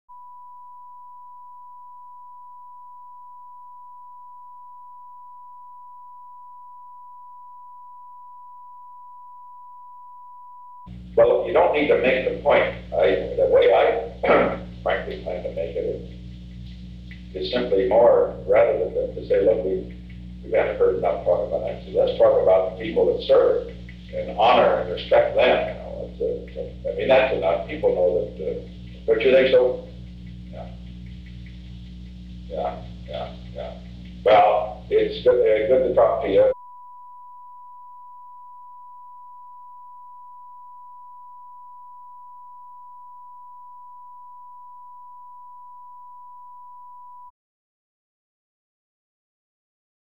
Recording Device: Old Executive Office Building
On August 22, 1972, President Richard M. Nixon and Ronald W. Reagan met in the President's office in the Old Executive Office Building from 10:04 am to 10:06 am. The Old Executive Office Building taping system captured this recording, which is known as Conversation 361-006 of the White House Tapes.
The President talked with Ronald W. Reagan.